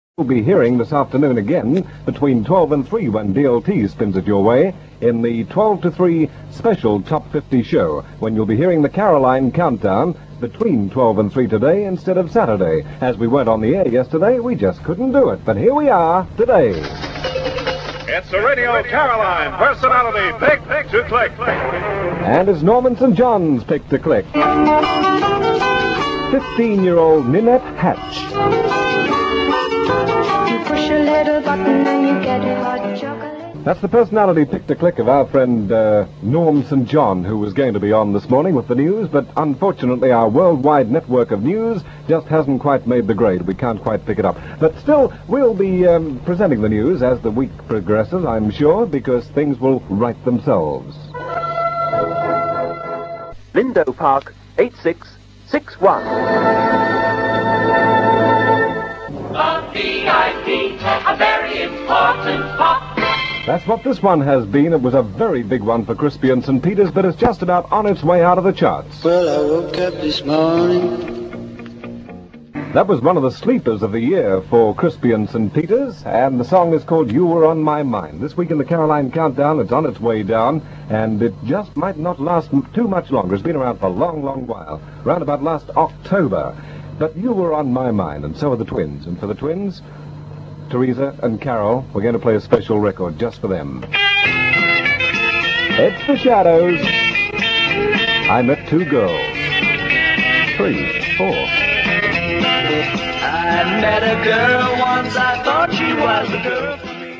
broadcasting from the Cheeta II in February 1966 - and demonstrating the problems they were facing: off the air yesterday and no news today.